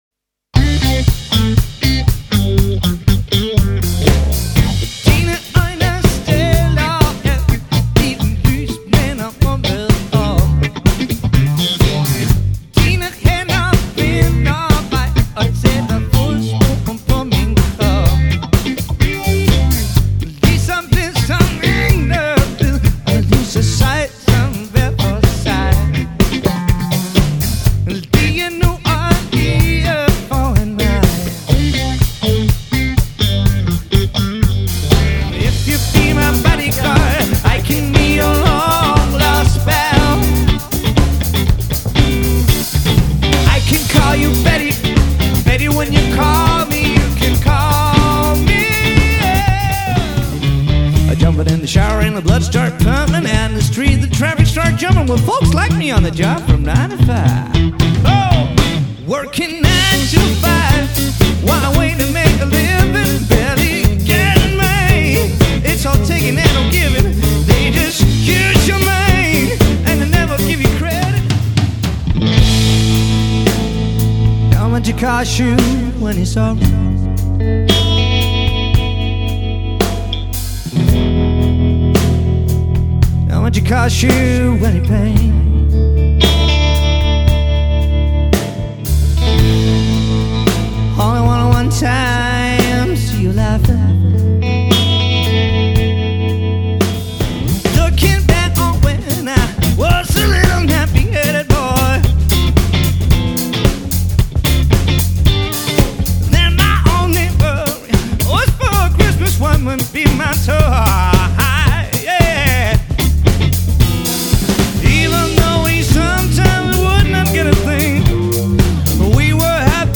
• Coverband